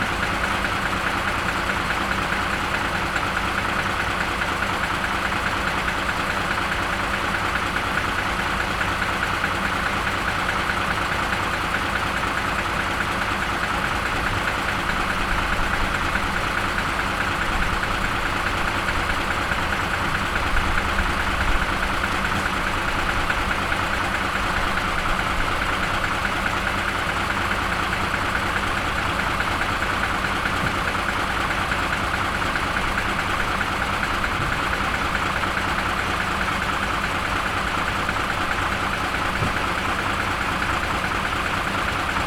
Title Duration Download Files Permission Truck idling 00:42 wav aif ogg mp3 Full 18-wheeler pulls away 00:05 wav PD Big rig ignition and revving 00:08 wav PD Big rig passes by 00:04 wav PD Air brakes 00:06 wav PD
Truck_Idling_01.ogg